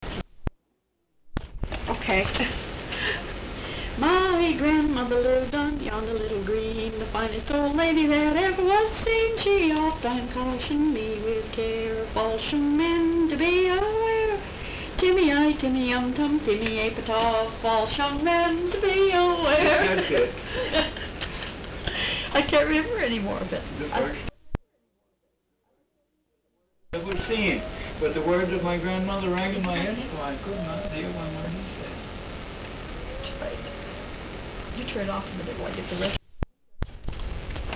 Folk songs, English--Vermont
sound tape reel (analog)